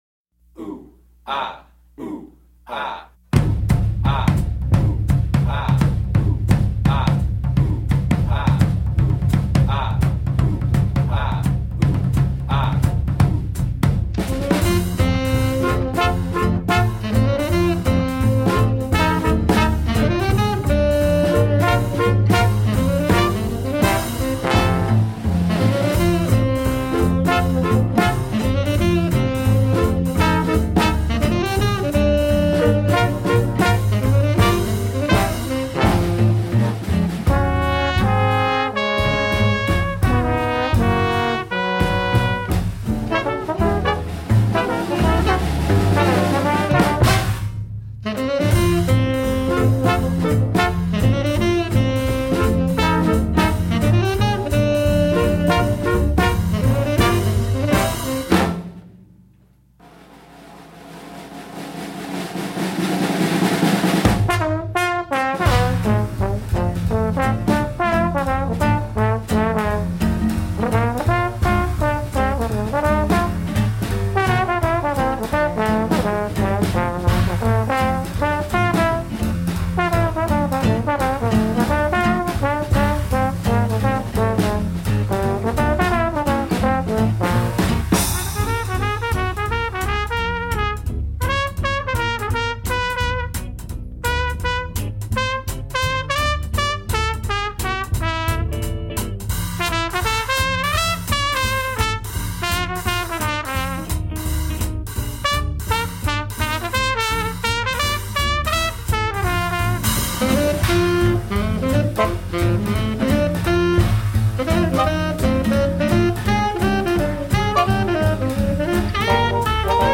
Vintage swing in the 21st century.